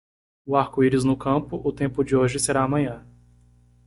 Pronounced as (IPA) /ˈaʁ.ku/